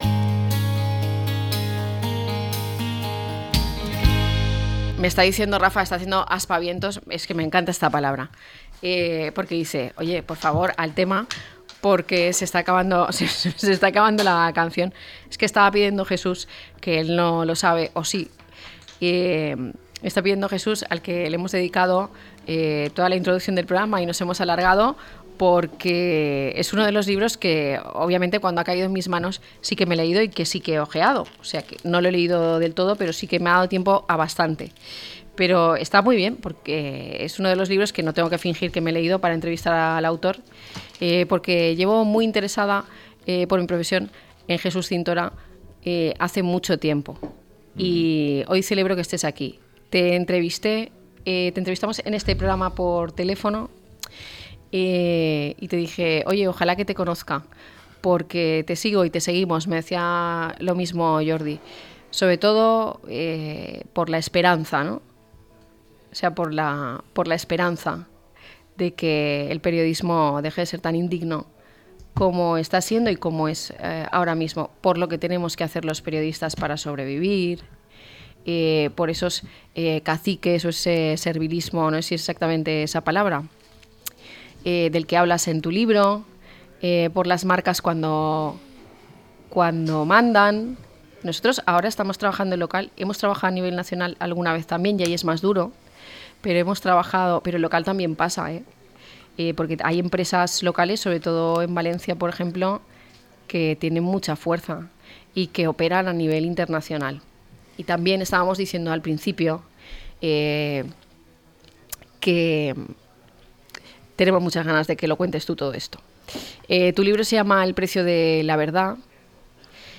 En su nuevo libro el autor explica por qué la desinformación y la propaganda se han convertido en graves amenazas para las democracias. El periodista Jesús Cintora nos relata cómo funcionan los medios desde dentro, los peligros de la desinformación, los poderes ocultos tras la polarización que vivimos y las razones de por qué la ciudadanía tiene que estar más atenta que nunca.